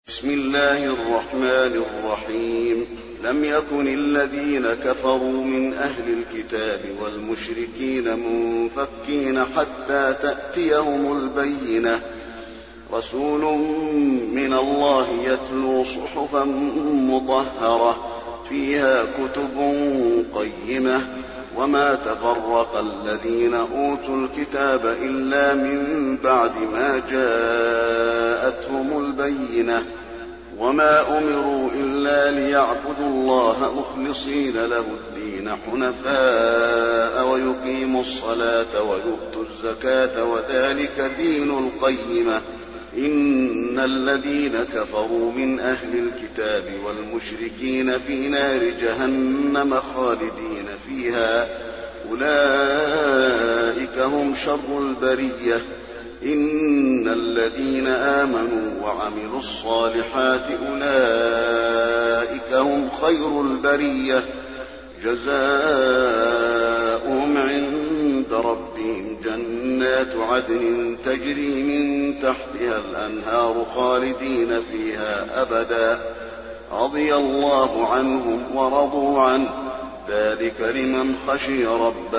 المكان: المسجد النبوي البينة